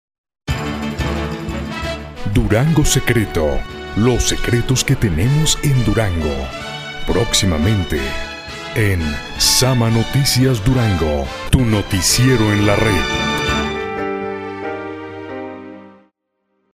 locutor versatil tanto para voz comercial, institucional, y doblaje
locutor profesional perfecto español
kastilisch
Sprechprobe: Sonstiges (Muttersprache):